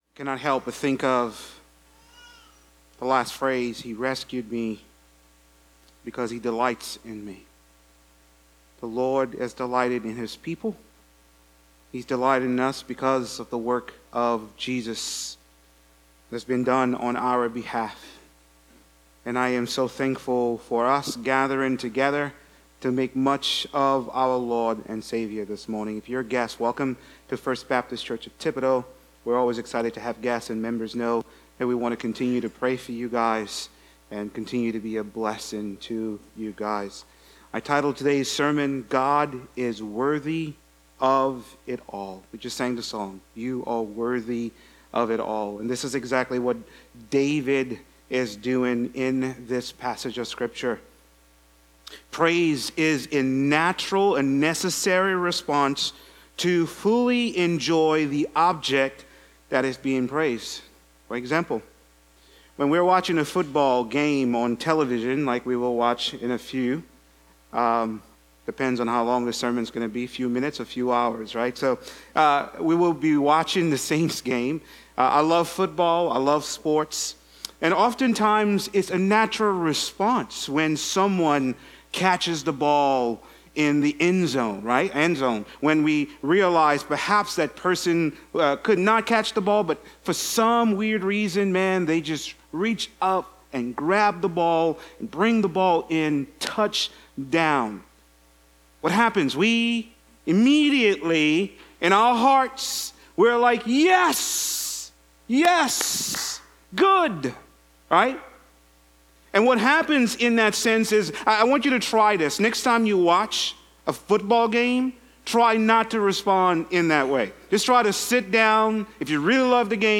A message from the series "The Book of 2 Samuel."